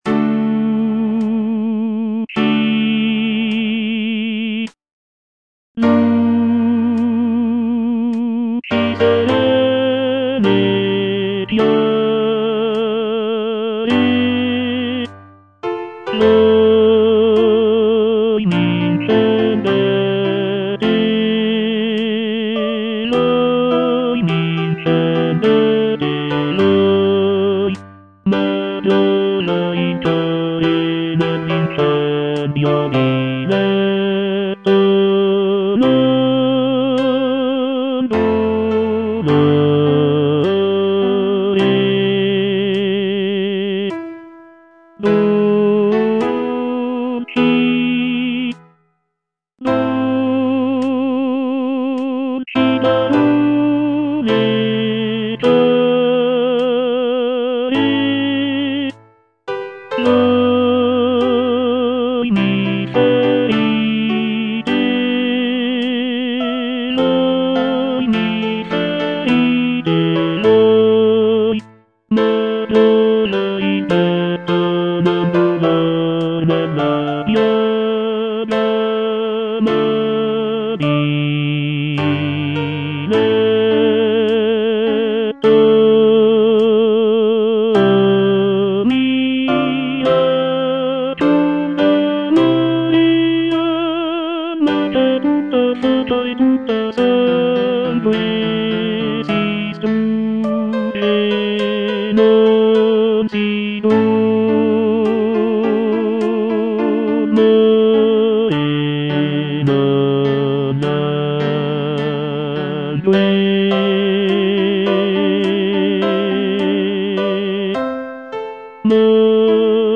C. MONTEVERDI - LUCI SERENE E CHIARE Tenor (Voice with metronome) Ads stop: auto-stop
"Luci serene e chiare" is a madrigal composed by Claudio Monteverdi, one of the most important figures in the development of Baroque music.
The madrigal is known for its intricate vocal lines and rich textures, creating a sense of serenity and clarity in the music.